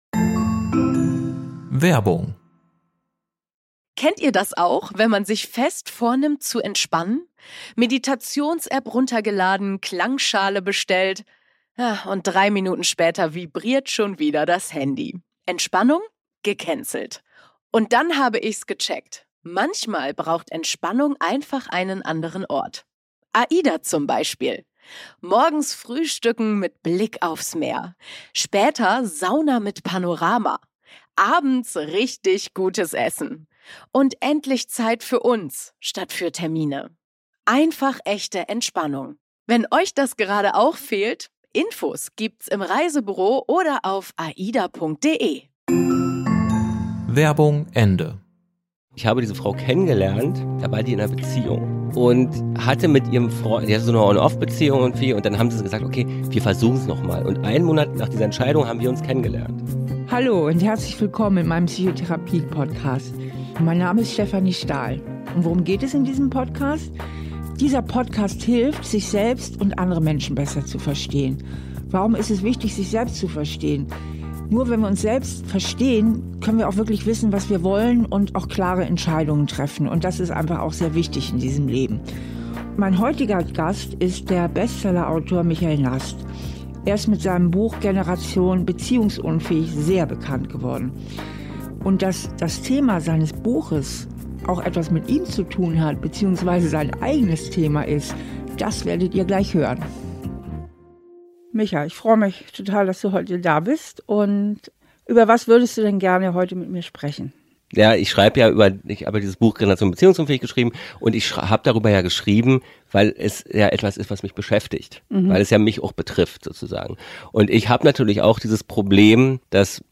Autor Michael Nast setzt sich bei Stefanie Stahl auf die Couch.